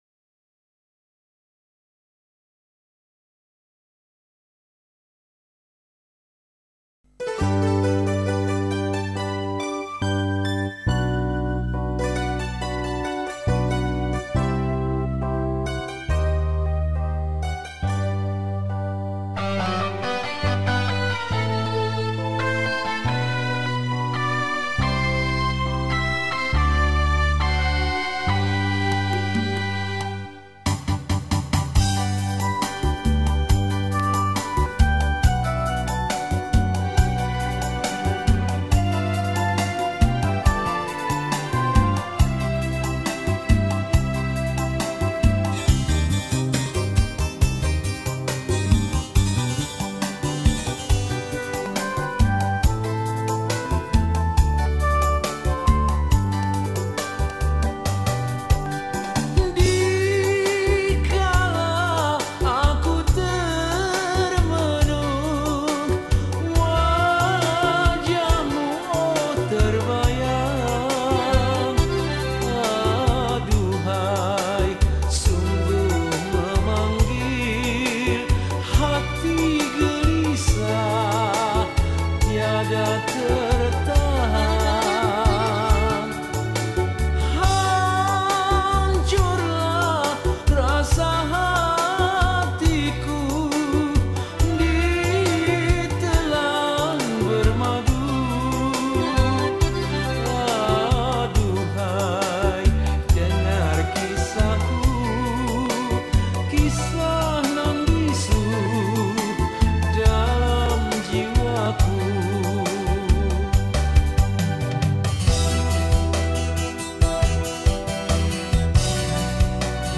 Indonesian Song
Solo Recorder